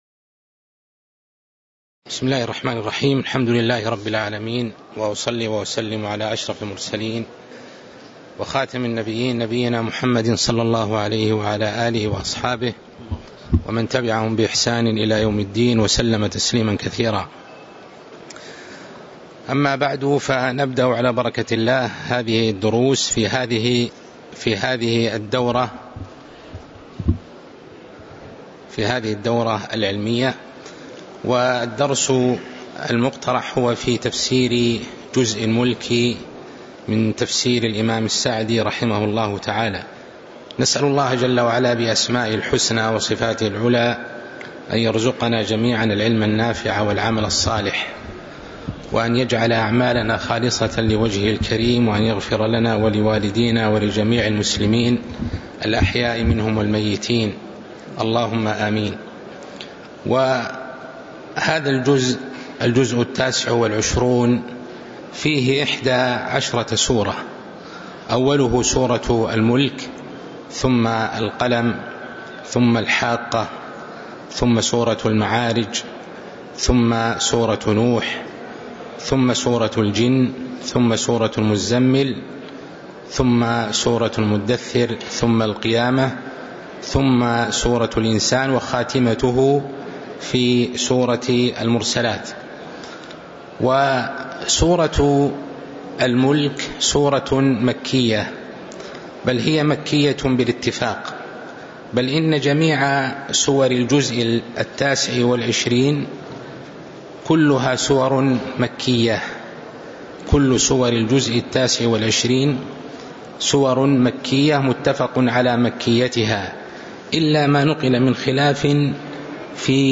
تاريخ النشر ٩ ربيع الثاني ١٤٤٣ هـ المكان: المسجد النبوي الشيخ